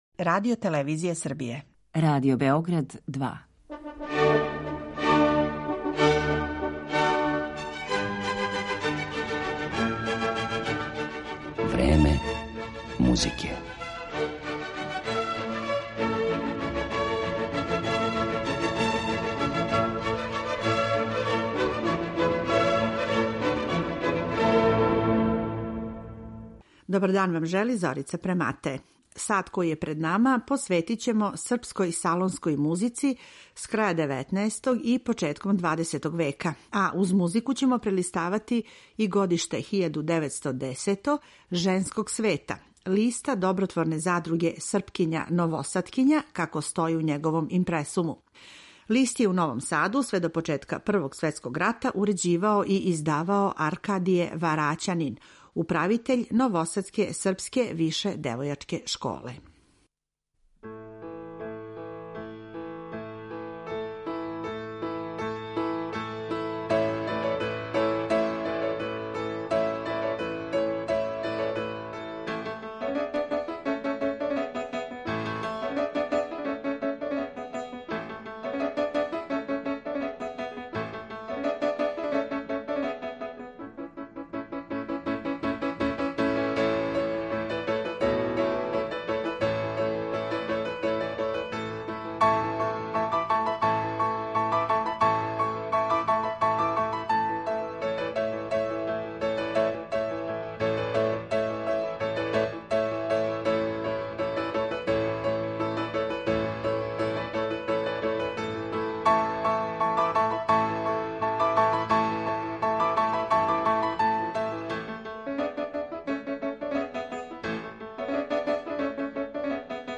Музичка пратња овим малим причама биће соло песме и клавирске минијатуре наших композитора, али и првих наших композиторки, њихових савременица.